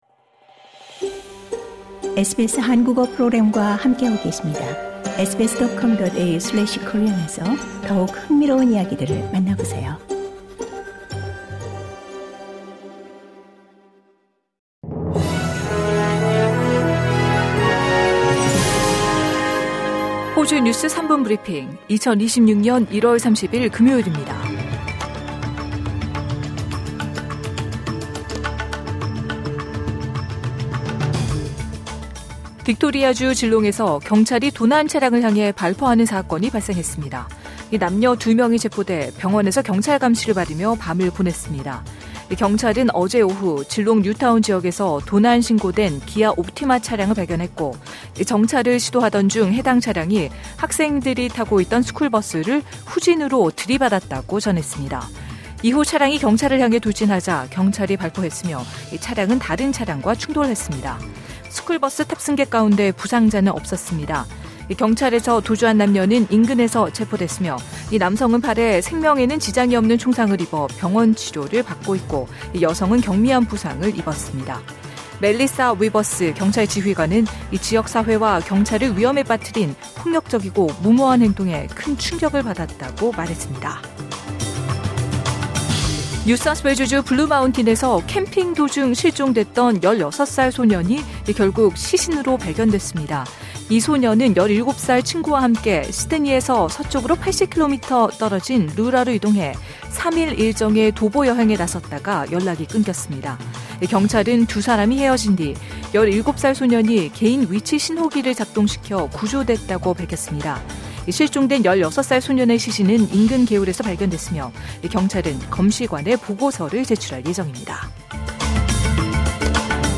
호주 뉴스 3분 브리핑: 2026년 1월 30일 금요일